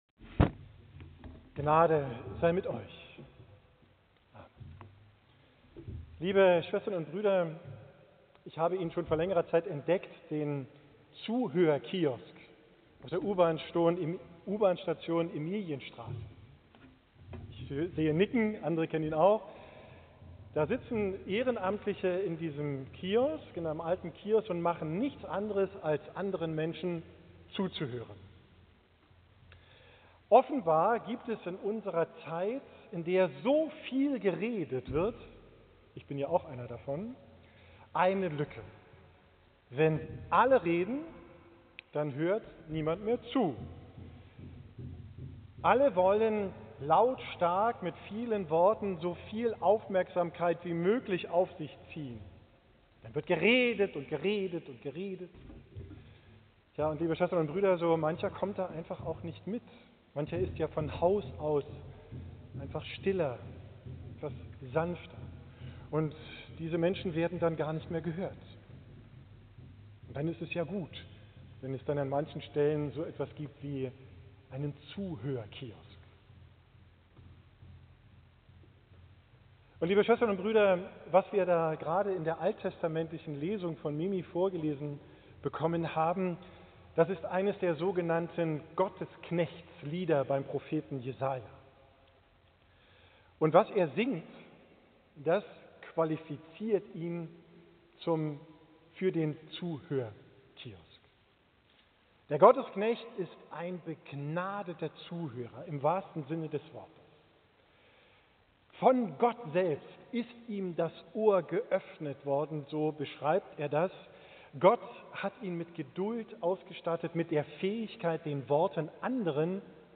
Predigt vom Sonntag Plamarum, 13.